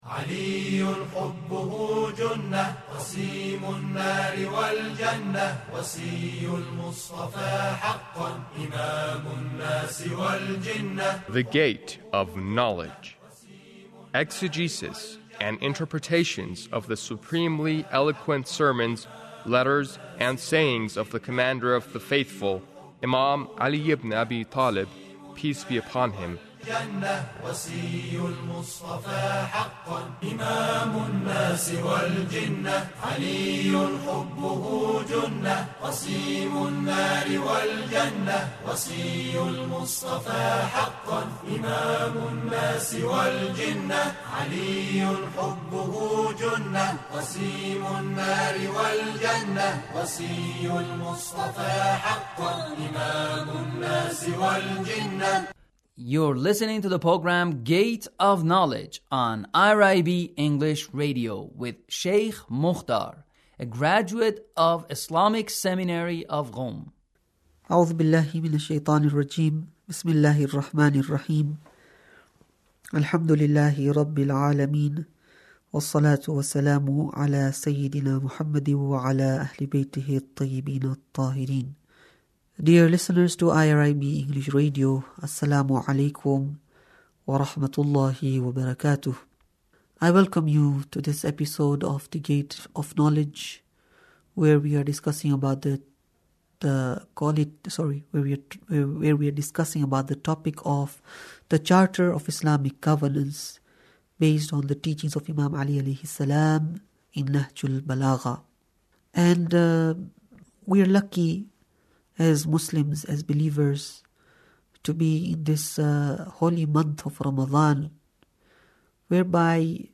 Sermon 1 - The Koran as a revolutionary book 19